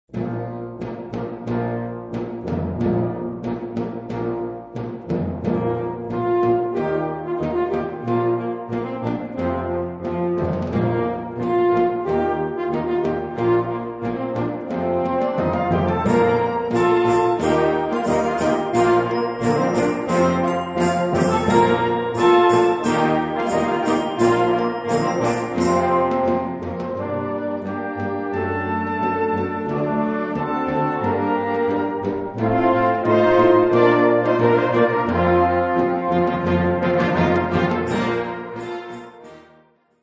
Category Concert/wind/brass band
Instrumentation Ha (concert/wind band)